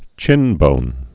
(chĭnbōn)